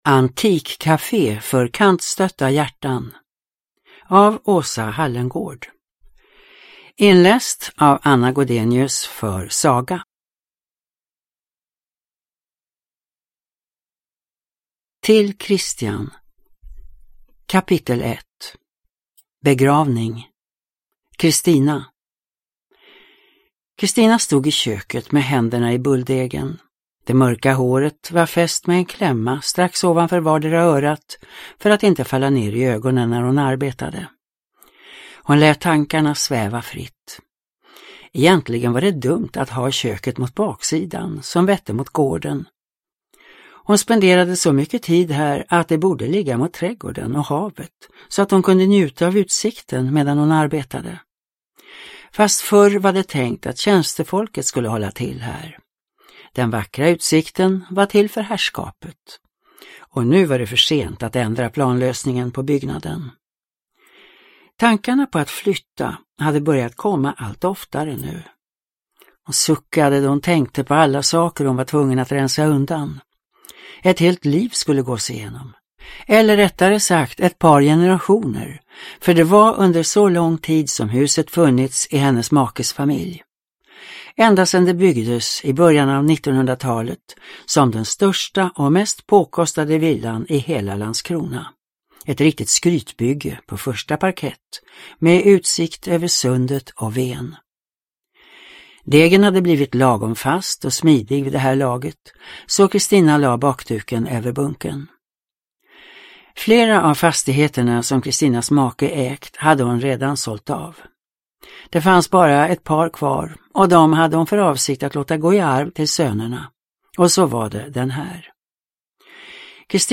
Antikcafé för kantstötta hjärtan / Ljudbok